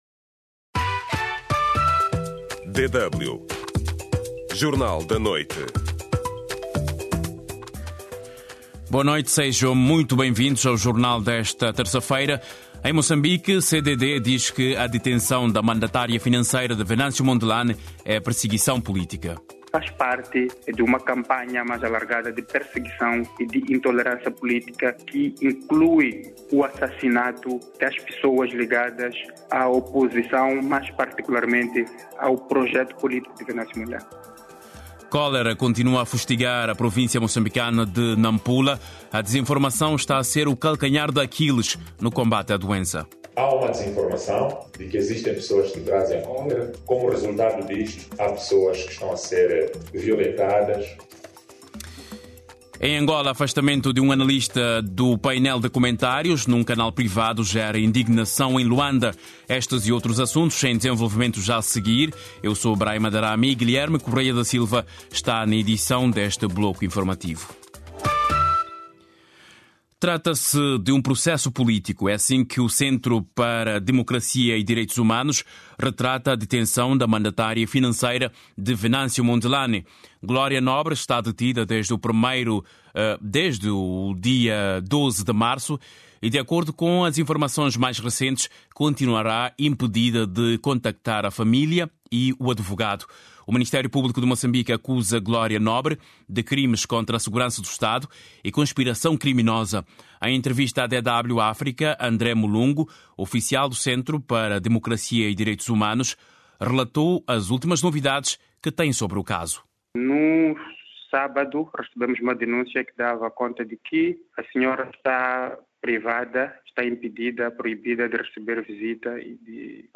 … continue reading 155 episode # Notícias # Notícias Diárias # Portugal